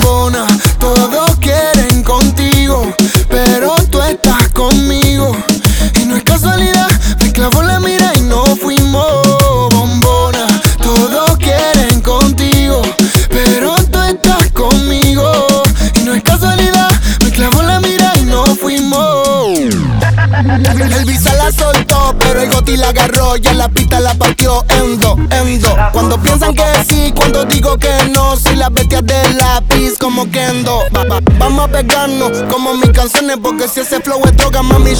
Жанр: Латиноамериканская музыка / Русские
# Urbano latino